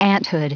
Prononciation du mot aunthood en anglais (fichier audio)
Prononciation du mot : aunthood